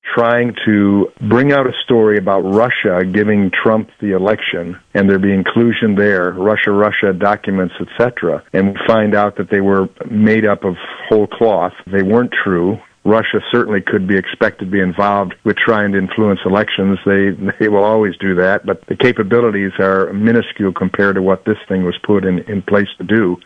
Jackson, Mich. (WKHM) — US Congressman Tim Walberg joined A.M. Jackson this past Friday morning, and he spoke about the recent news regarding Tulsi Gabbard declassifying documents related to a supposed collusion between President Trump and Russia in the 2016 election. Congressman Walberg says the documents show those allegations were simply not true.